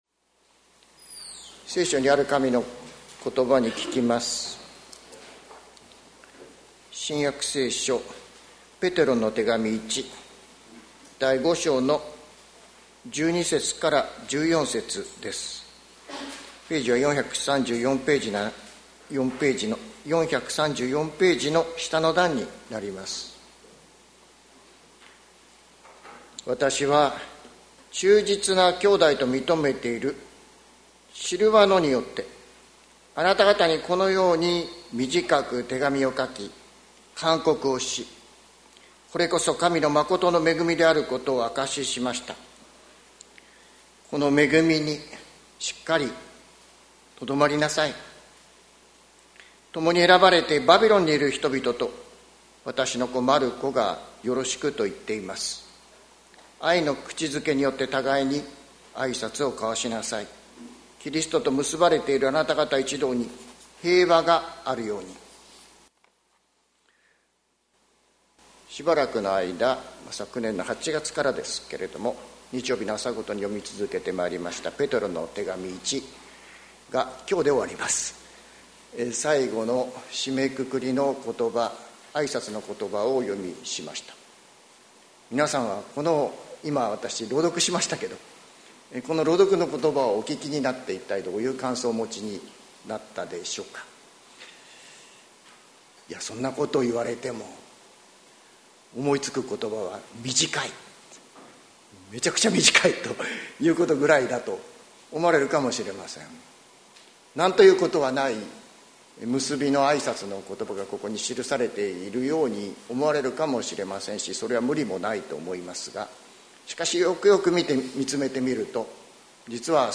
2025年03月02日朝の礼拝「踏みとどまるところ」関キリスト教会
説教アーカイブ。